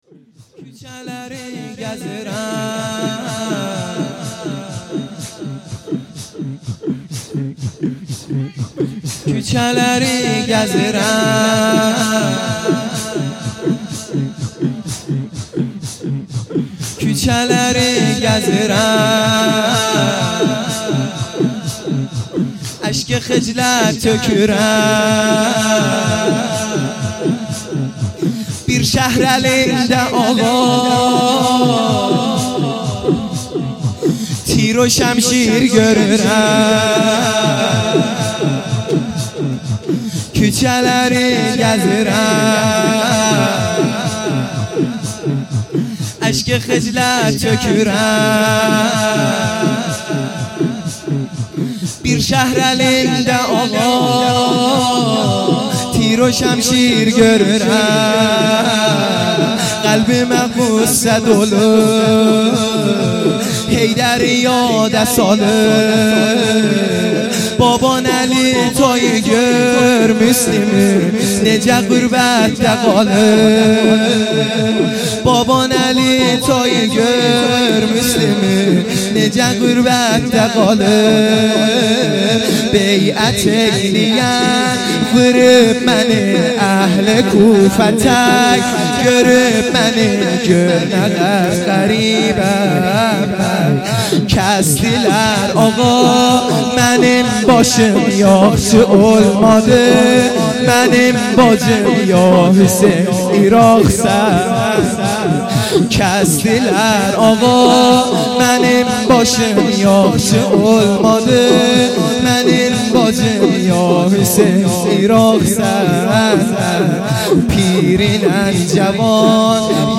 شور | کوچلری گزیرم
شب اول محرم الحرام ۱۳۹۶